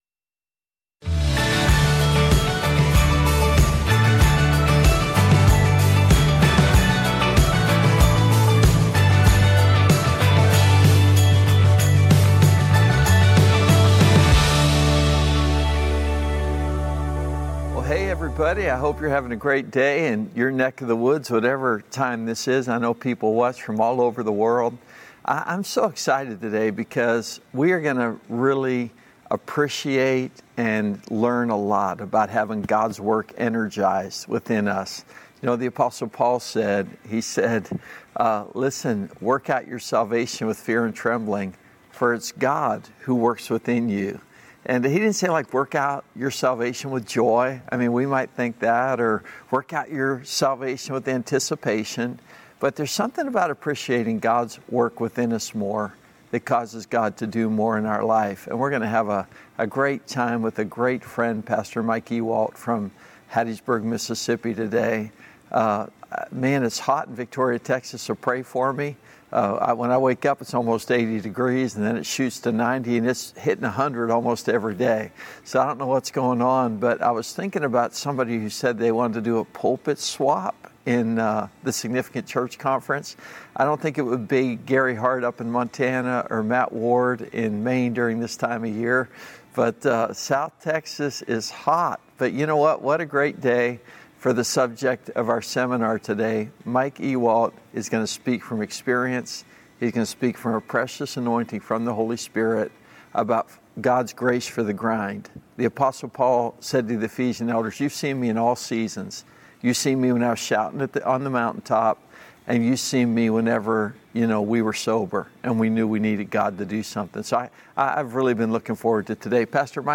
Significant_Leader_Webinar-Grace_for_the_Grind.mp3